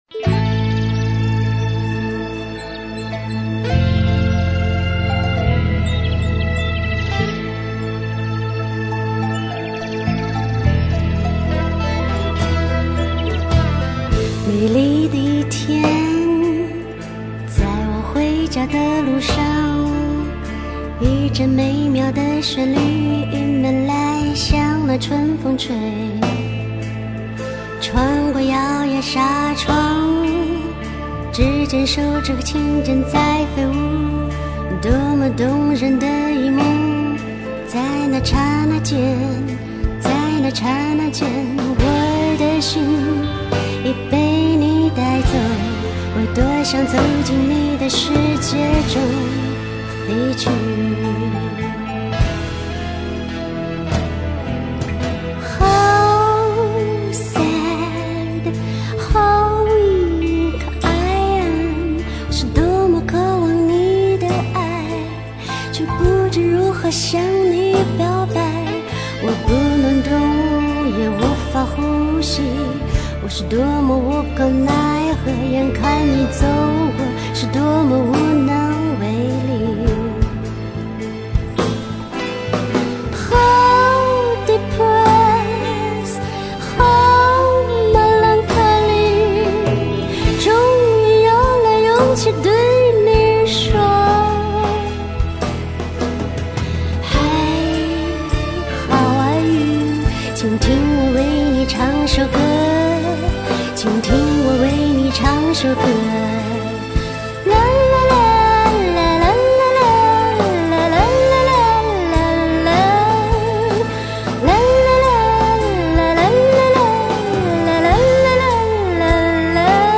曼妙悠扬的旋律、灵动甜美的嗓音、东西方文化与音乐元素的完美融合、美声——民族——通俗——原生态多种唱法的融会贯通